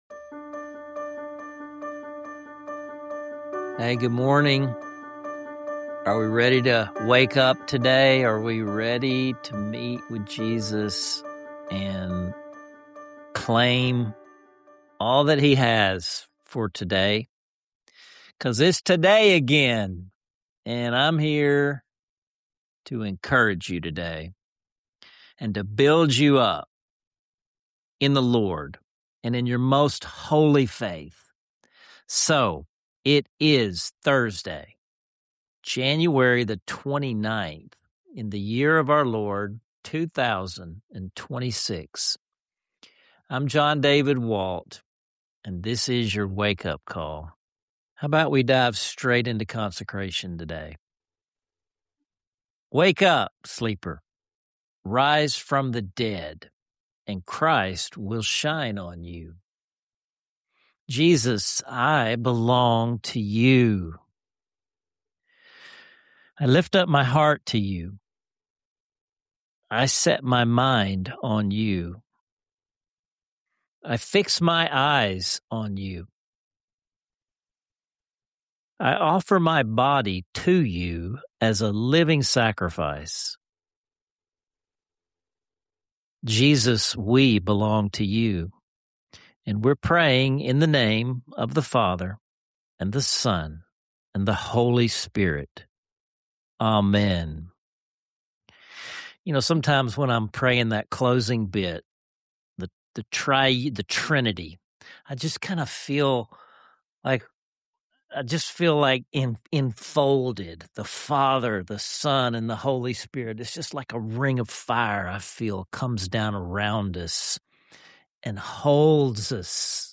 A soul-stirring time of prayer, music, and honest questions designed to spark deeper journaling and spiritual growth.